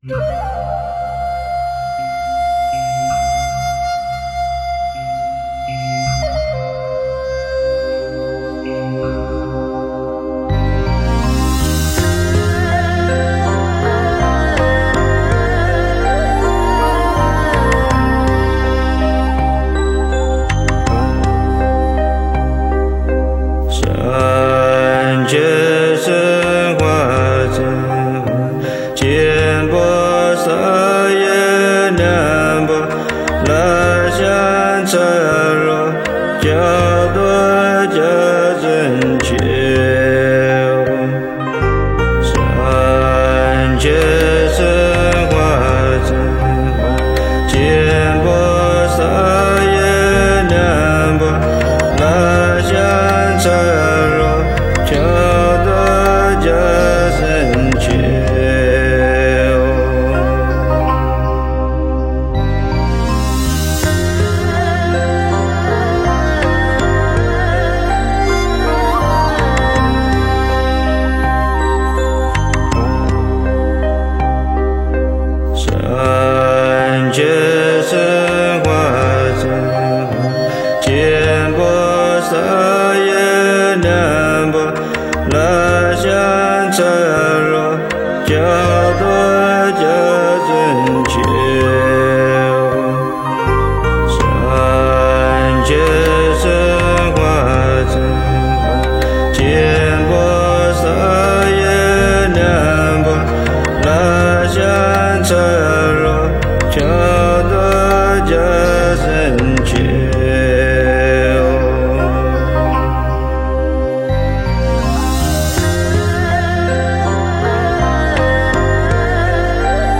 佛音 诵经 佛教音乐 返回列表 上一篇： 大悲咒(梵音